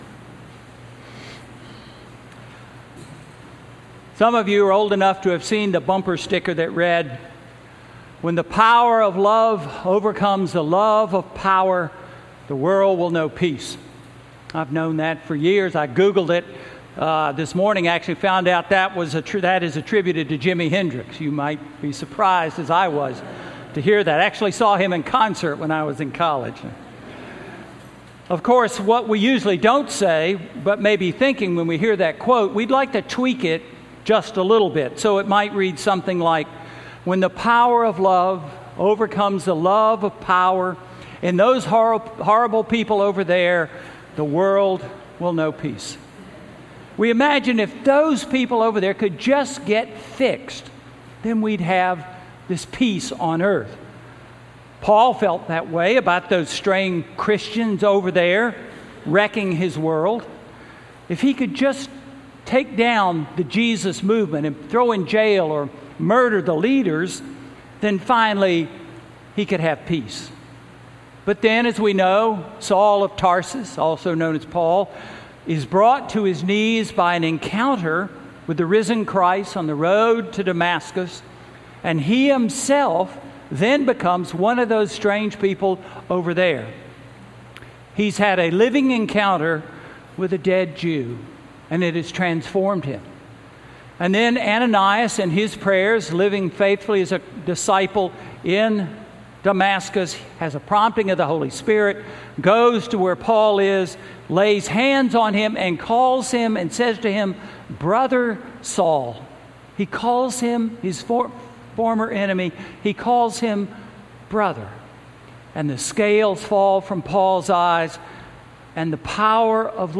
Sermon–Feelings versus Action–July 29, 2018